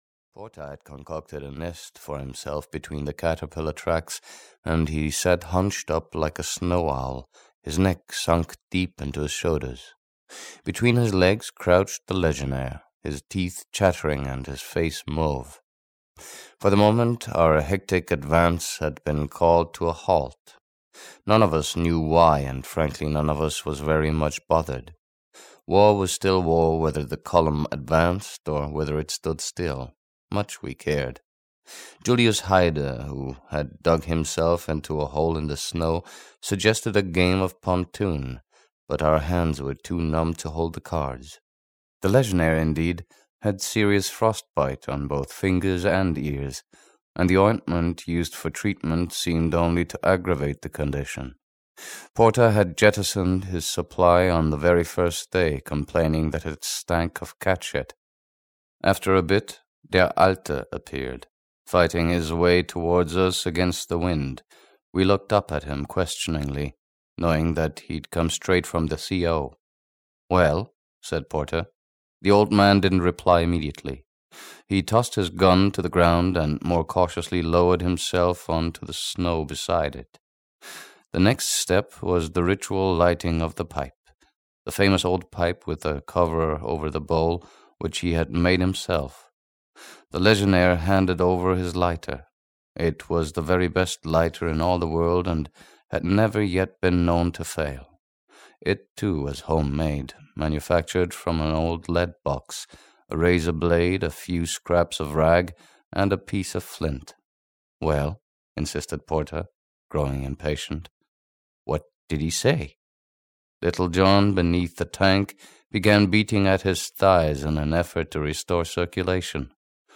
Marchbattalion (EN) audiokniha
Ukázka z knihy